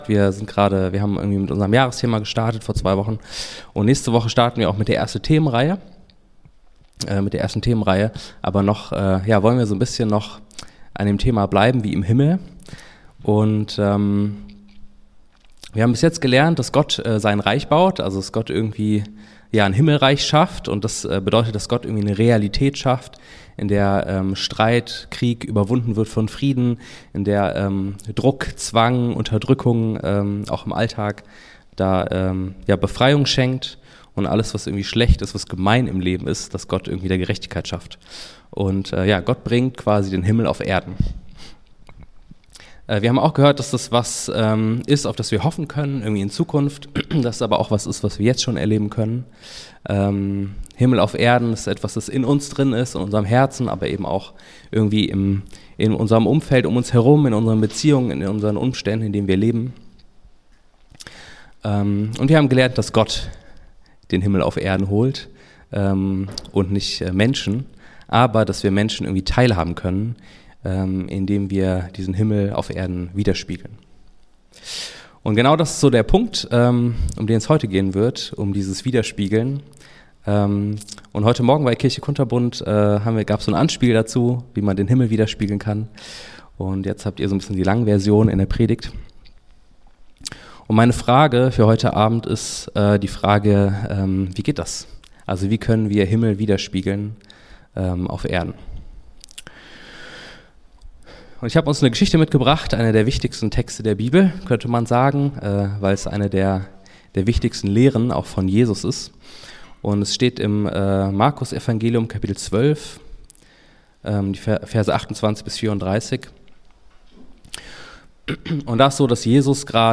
Gott schafft den Himmel auf die Erde. Darum ging es an unserem Visionssonntag. Wir können diesen Himmel widerspiegeln.